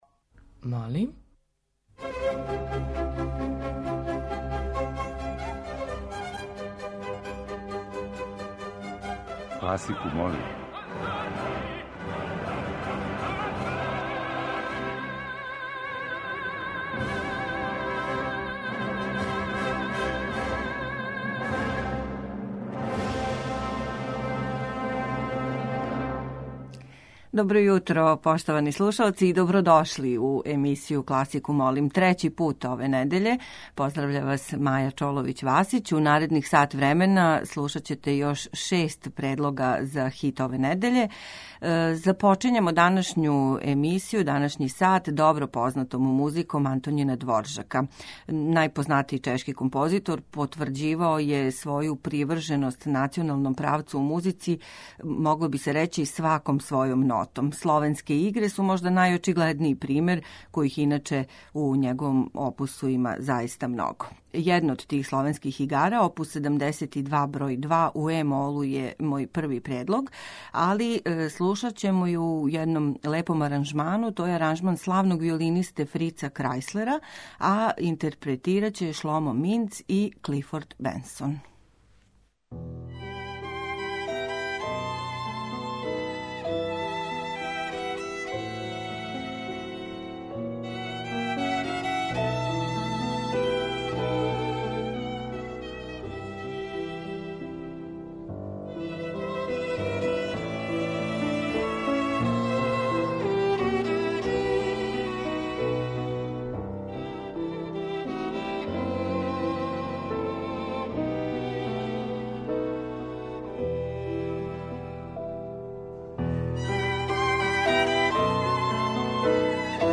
Средњевековне и ренесансне игре
Још један избор најразноврснијих музичких фрагмената за хит недеље биће обједињен средњовековним и ренесансним играма.